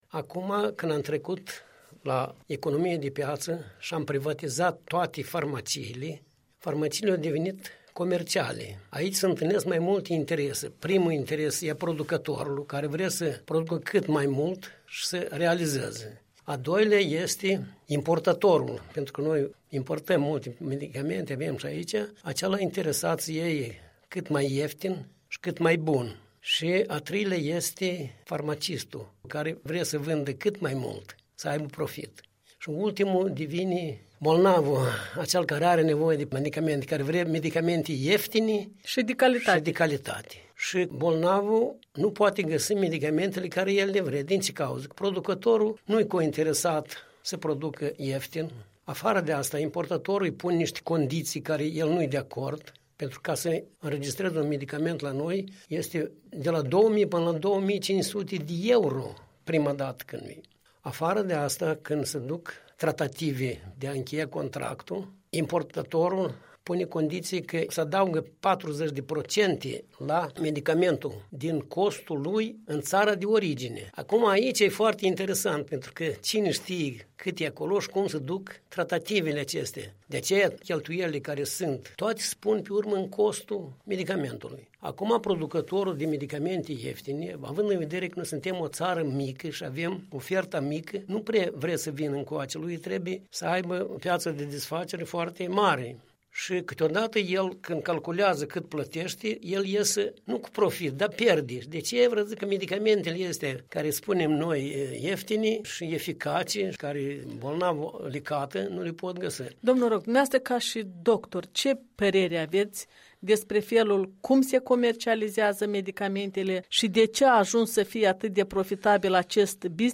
Un interviu